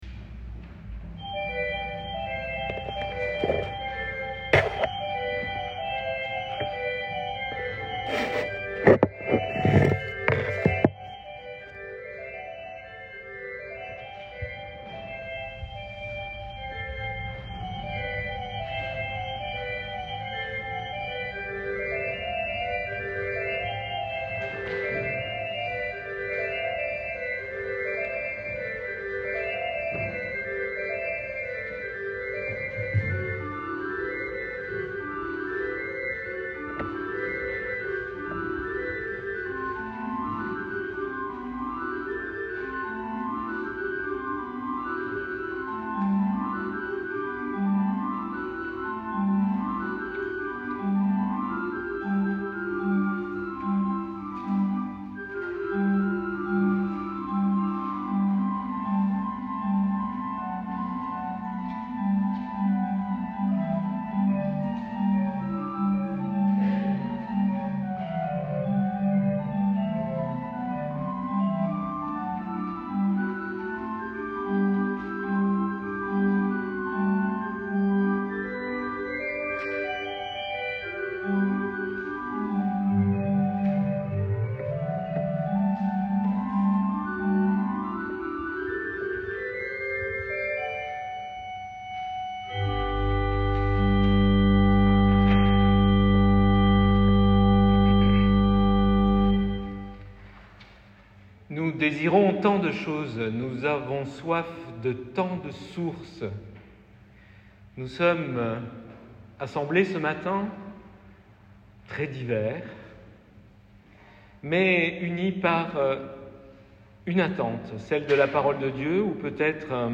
Audio, prédication à 14'49
ORGUE